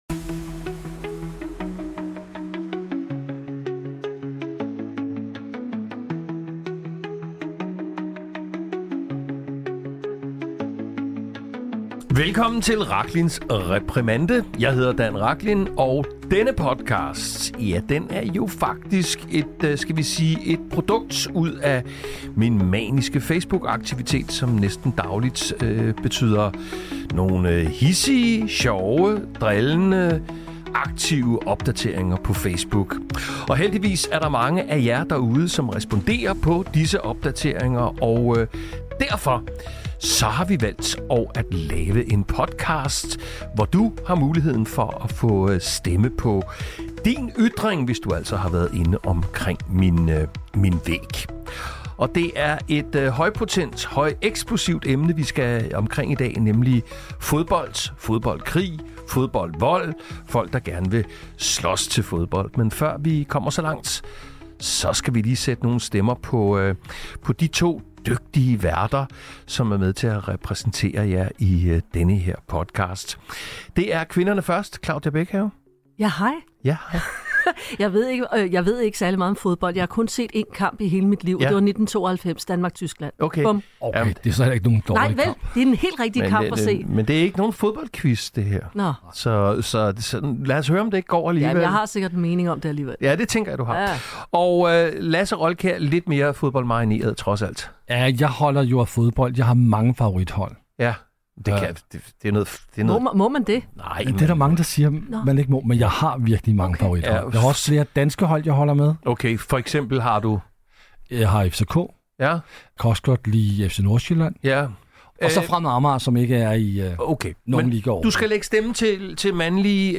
Som altid tilføjer vi ekstra dybde til samtalen, med AI der analyserer kommentarsporet og identificerer centrale temaer og diskurser.